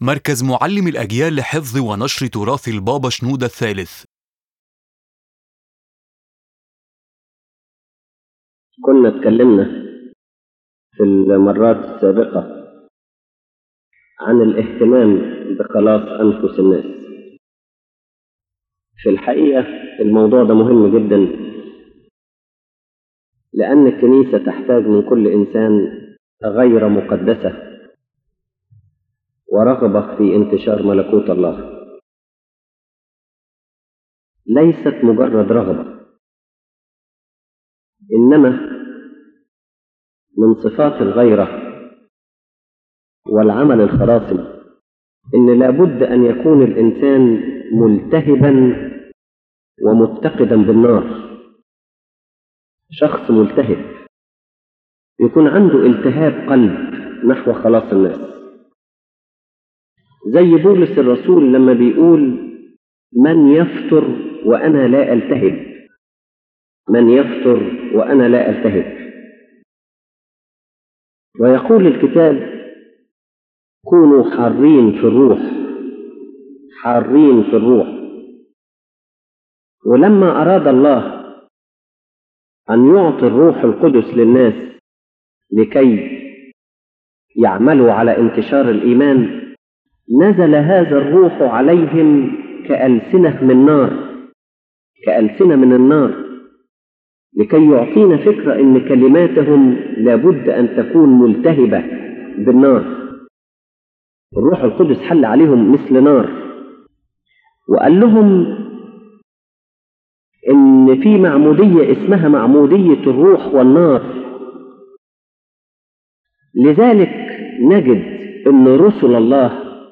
The lecture continues discussing holy zeal, focusing on its nature as a spiritual fire inside the human heart, driving him strongly and passionately toward the salvation of others and working in the Kingdom of God.